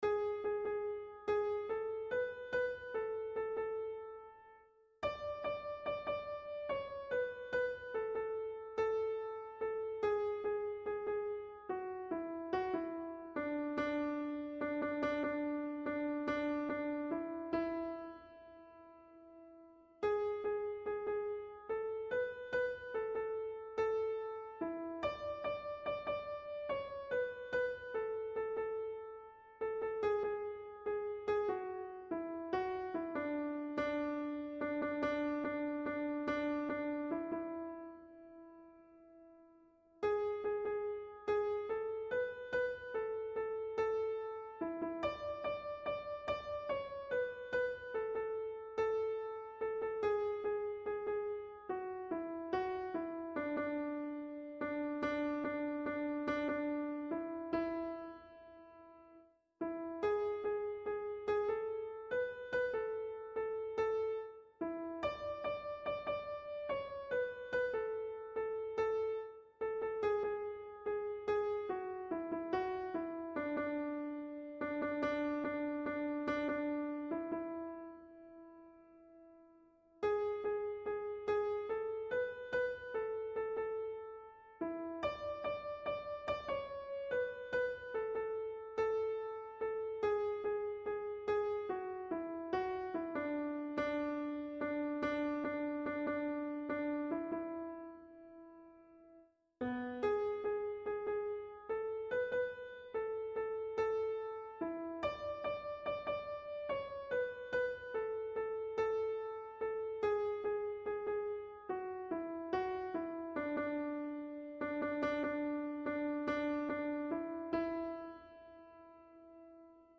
Click to hear MIDI-style audio file of tune.